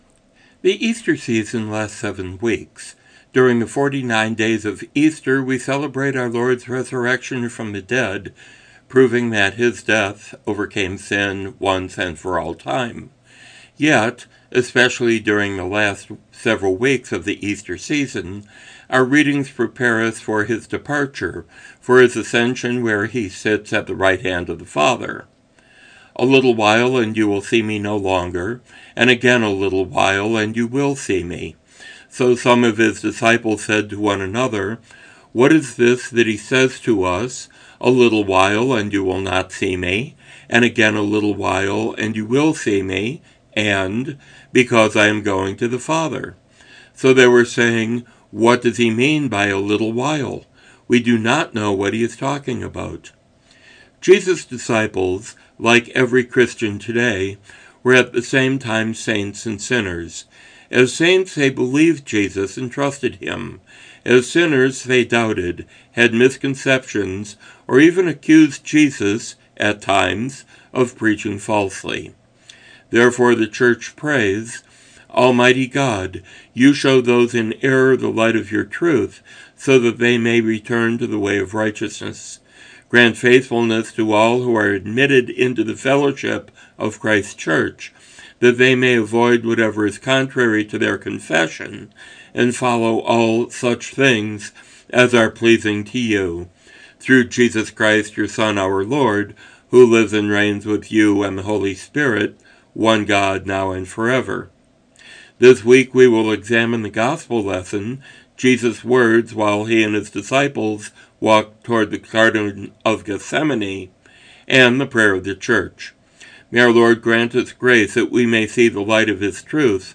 Daily devotions as heard on KJOE 106.1 FM
Broadcast